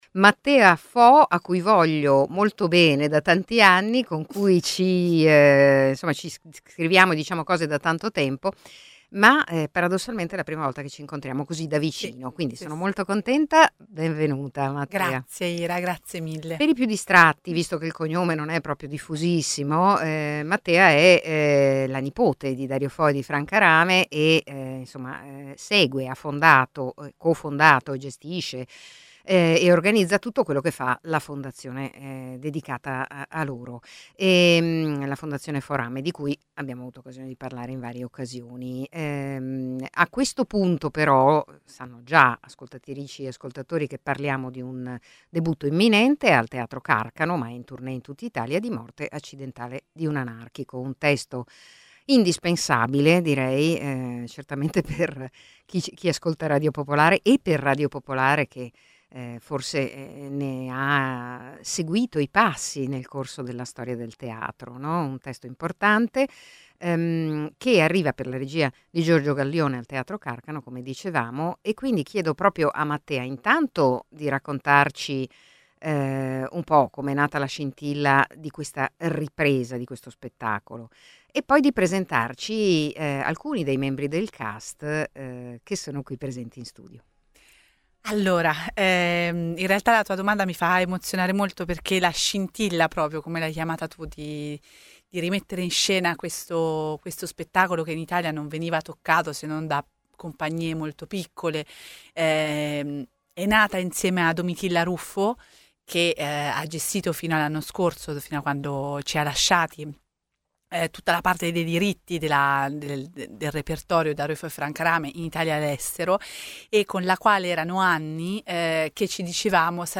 alcuni membri del cast dello spettacolo